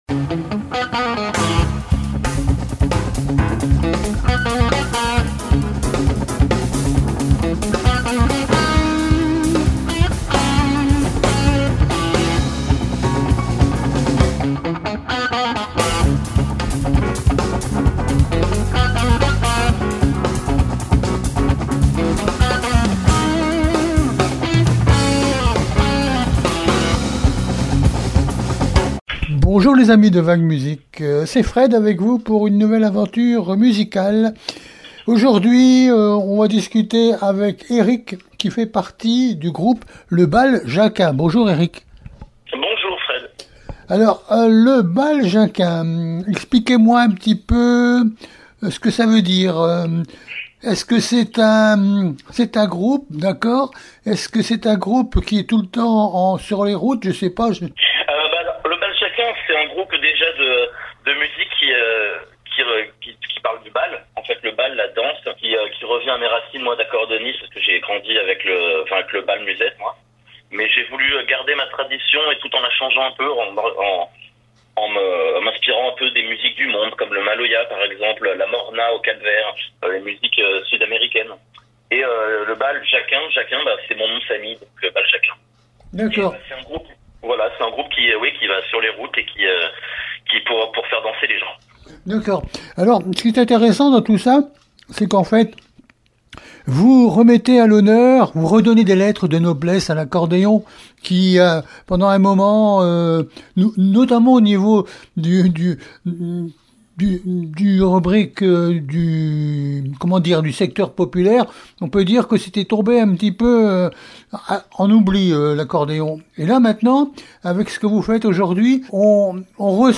LE BAL JACQUIN- VAG MUSIC - interview du 9 mars 2026